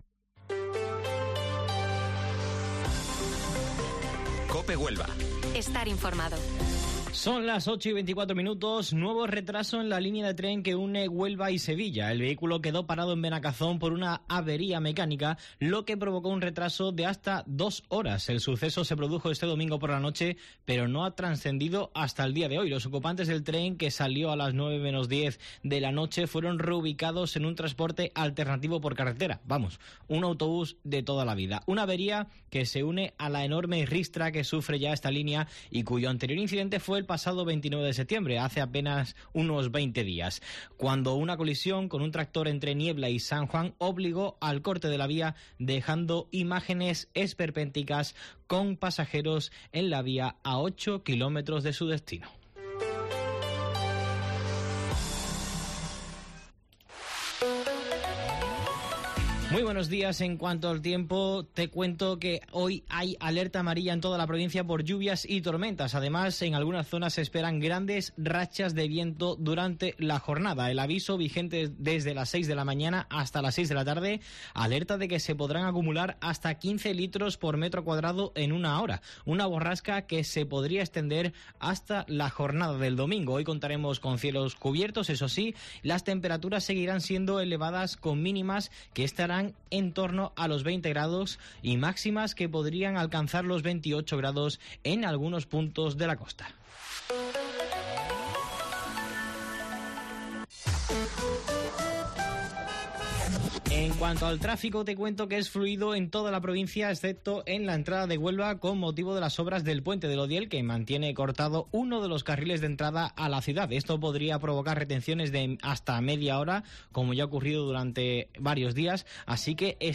AUDIO: Informativo Matinal Herrera en COPE Huelva 17 de octubre